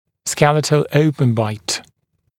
[‘skelɪtl ‘əupən baɪt][‘скелитл ‘оупэн байт]гнатический открытый прикус, скелетный открытый прикус